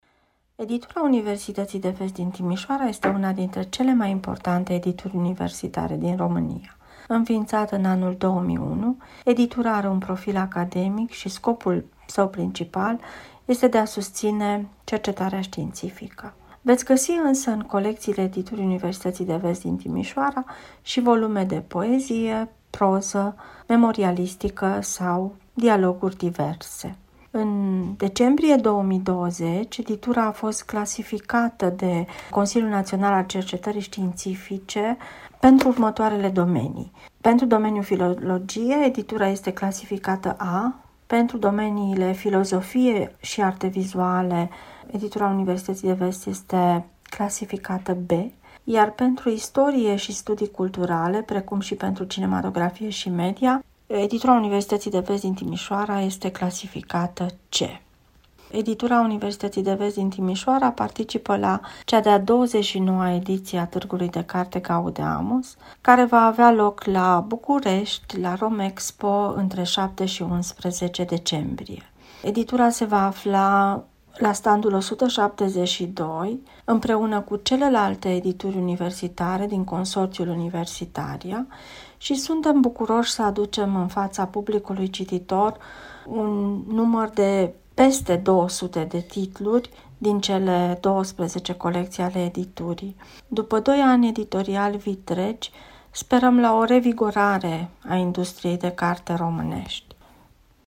(Interviuri Radio Timișoara, în exclusivitate.)